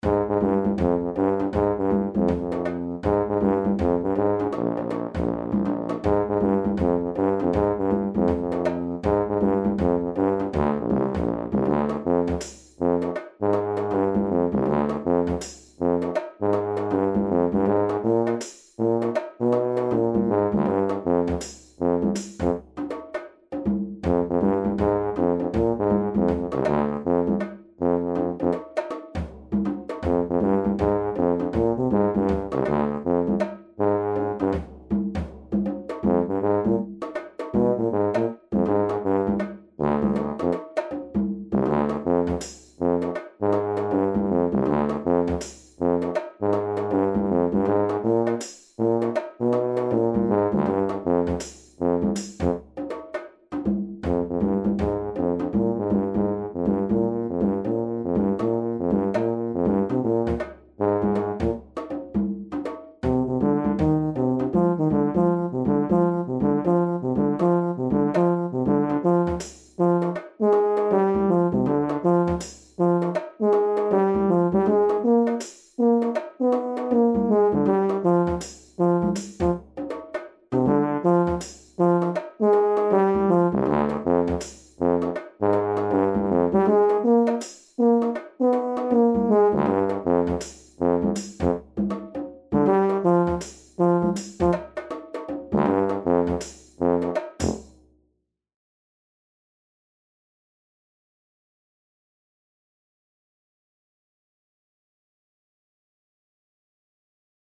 The fifth and final miniature from "Feel", a set of jazz miniatures for tuba and drums.
jive swing tuba percussion